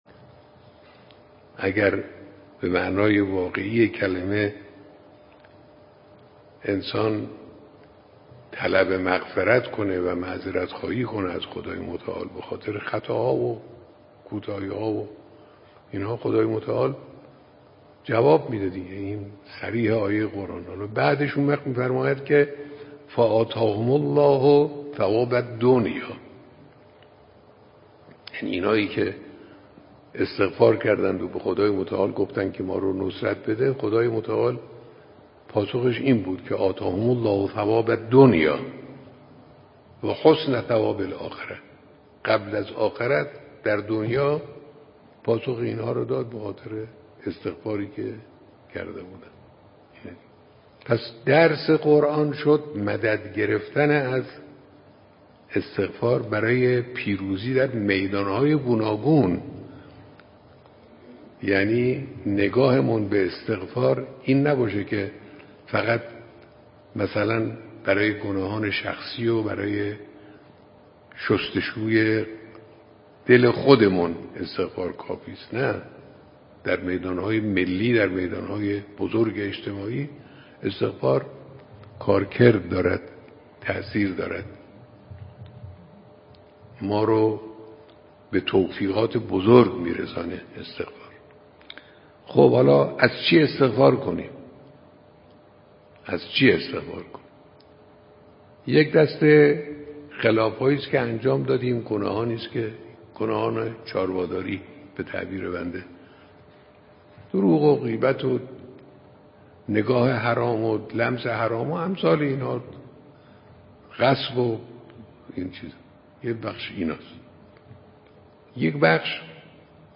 قطعه کوتاه صوتی از امام خامنه ای در خصوص استغفار